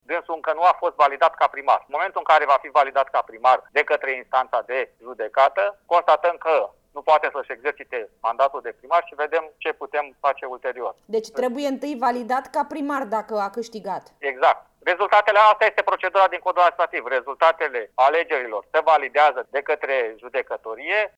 La Prefectura Olt, reprezentantul Guvernului, Florin Homorean, spune că noi alegeri nu pot fi organizate la Deveselu până ce mortul nu e declarat câștigător.